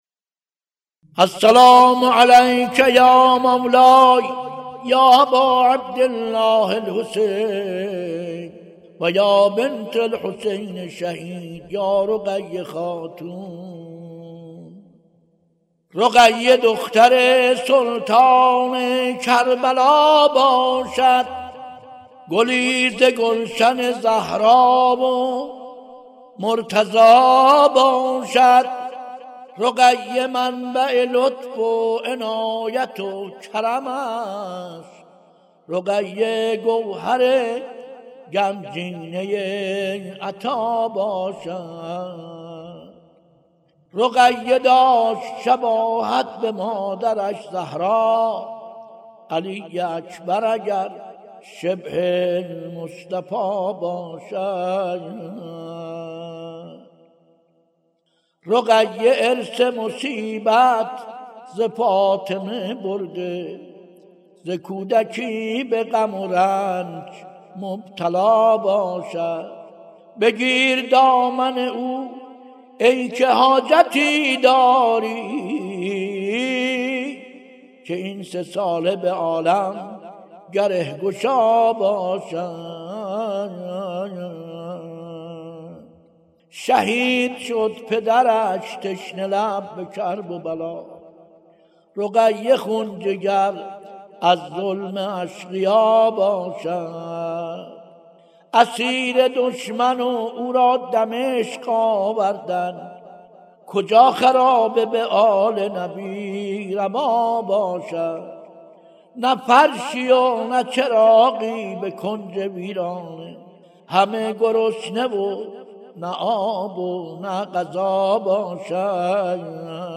روضه‌خوانی که برای گرفتن شعر، خودش را به مریضی زد + صوت